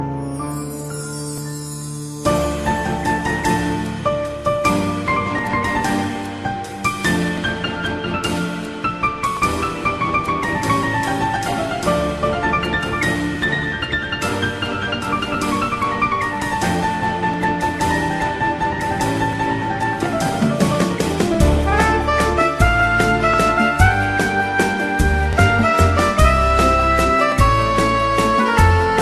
category : Bollywood